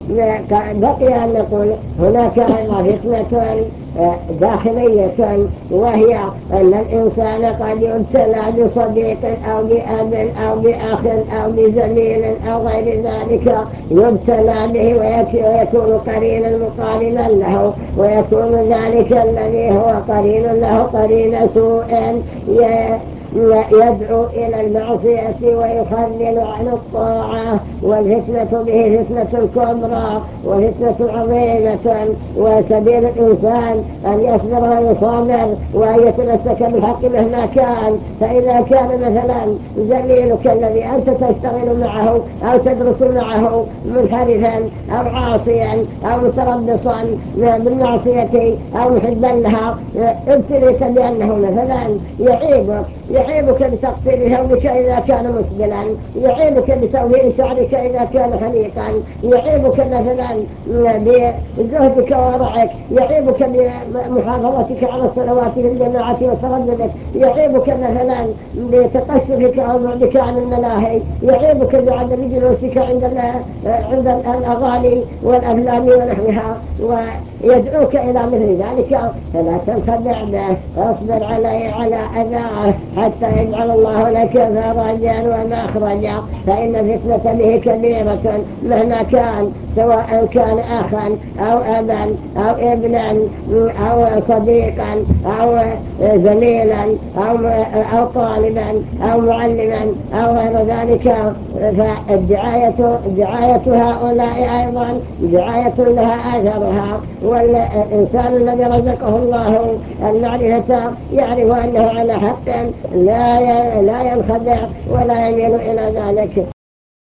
المكتبة الصوتية  تسجيلات - محاضرات ودروس  محاضرة في فتن هذا الزمان ومقاومتها